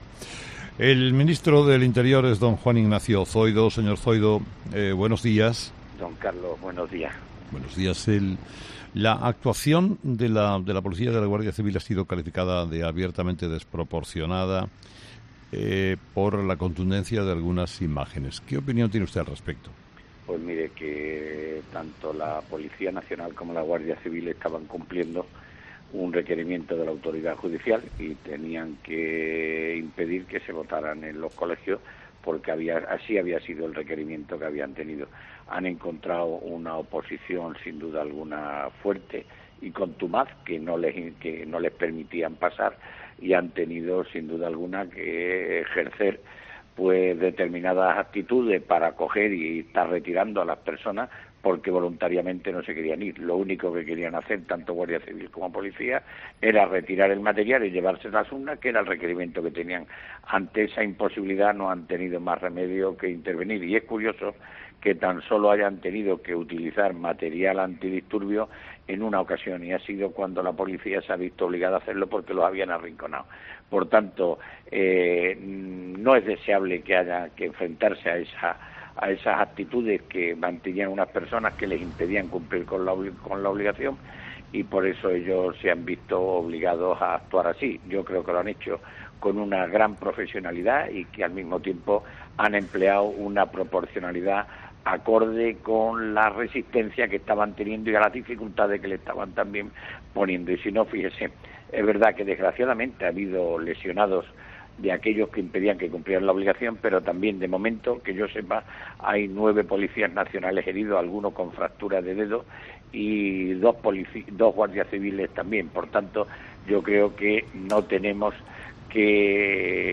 El Ministro del Interior ha señalado en el especial Informativo dirigido por Carlos Herrera que "la actuación de los Cuerpos y Fuerzas de Seguridad del Estado no ha sido desproporcionada"
Juan Ignacio Zoido, ministro del Interior sobre el 1-O